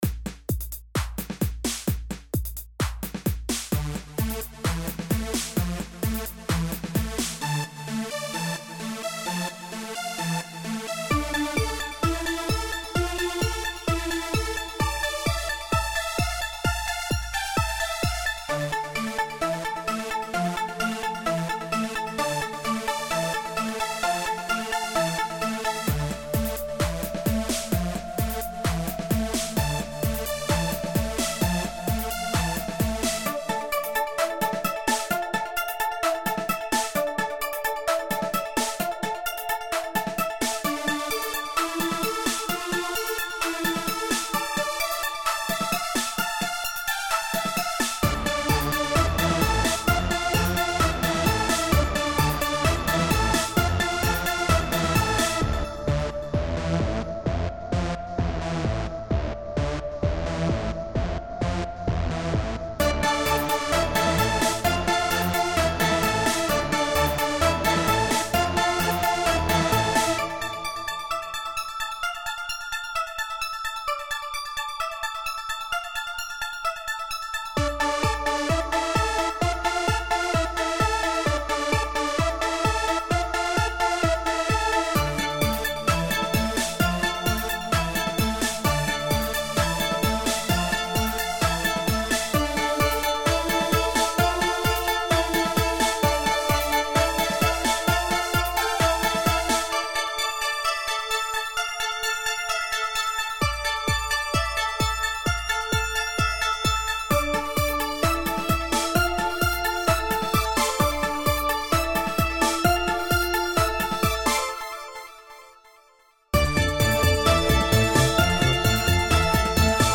A musical composition
I wrote it in Fruity Loops mostly because I like it for my techno songs.